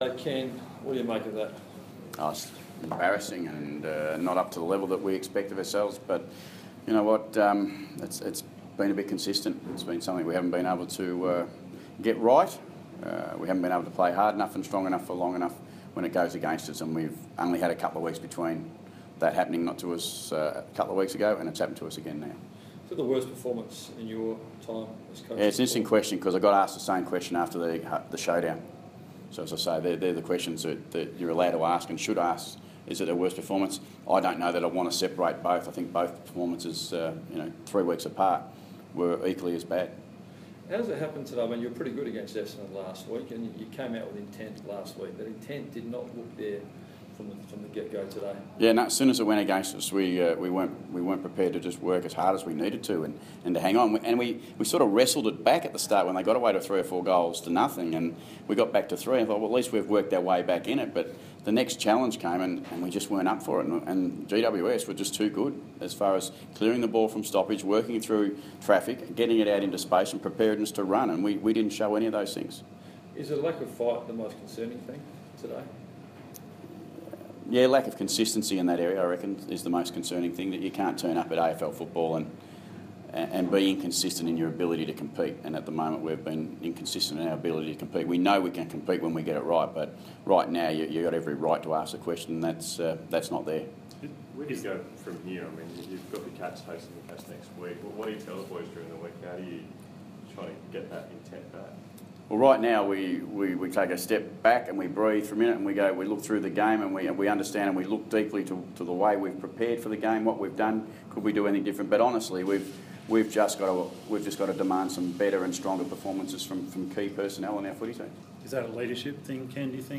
Ken Hinkley Post-match Press Conference - 17 April 2016
Ken Hinkley addresses media after Port Adelaide's loss to Greater Western Sydney